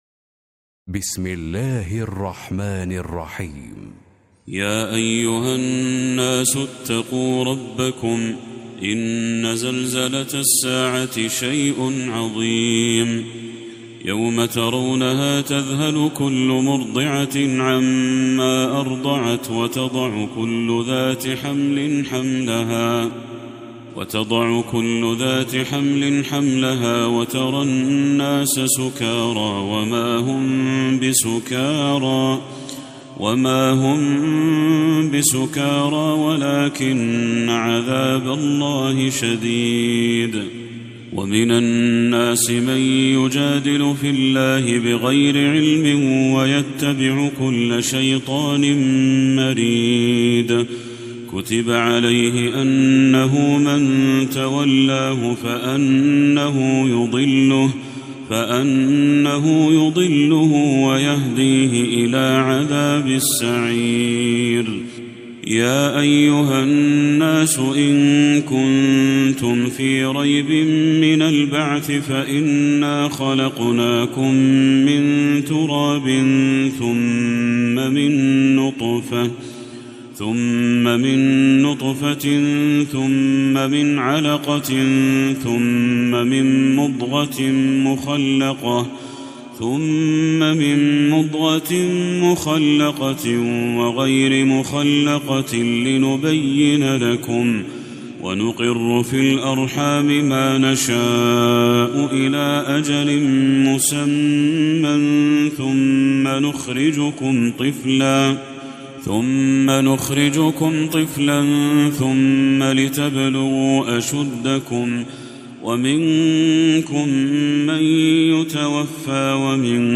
سورة الحج Surat Al-Hajj > المصحف المرتل